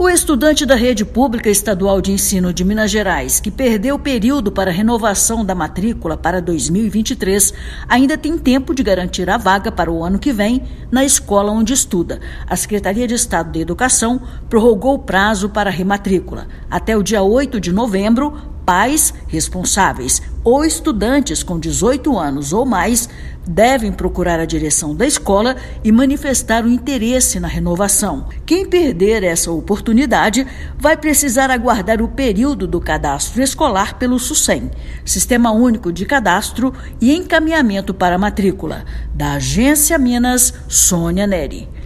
Alunos que desejam permanecer na mesma escola em 2023 e ainda não renovaram a matrícula agora têm até 8/11 para informar à direção da unidade o interesse. Ouça matéria de rádio.